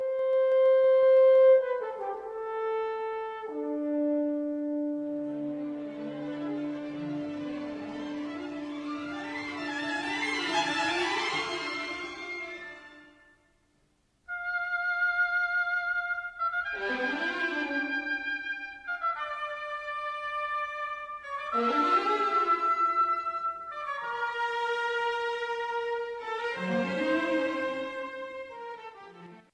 وی همچنین به ساخت موسیقی برای گروههای حرفه ای نیز ادامه می داد؛ “رقصهای ماروسک” ۱۹۳۰ برای سولوی پیانو و ارکستر، “رقصهای گالانتا” ۱۹۳۳ برای ارکستر، “گوناگونی طاووس” ۱۹۳۹ سفارش داده شده از طرف ارکستر آمستردام به مناسبت جشن پنجاهمین سالگرد آن و “غوغای کوتاه” (Missa Brevis) در سال ۱۹۴۴ برای تک خوان، گروه کر، ارکستر و ارگ، را در میان آثار مطرح دیگرش می توان نام برد.